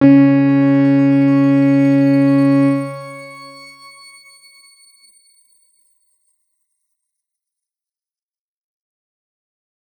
X_Grain-C#3-mf.wav